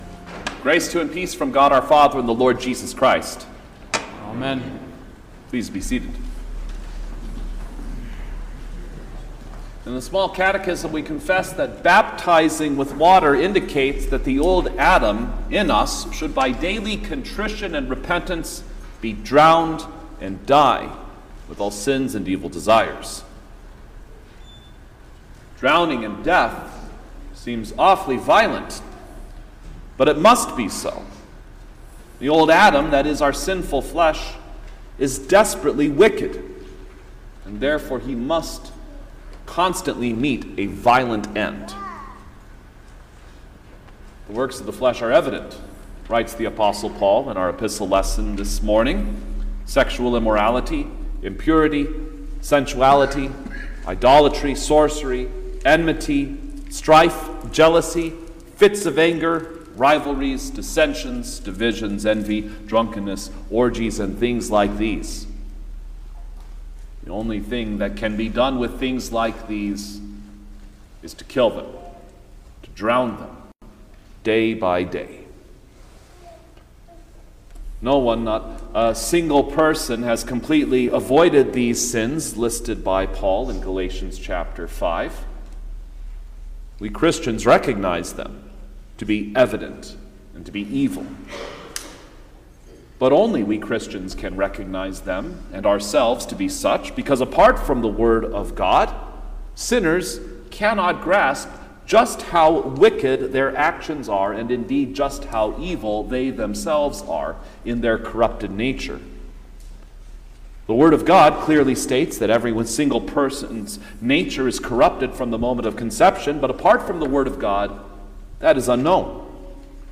September-1_2024_Fourteenth-Sunday-after-Trinity_Sermon-Stereo.mp3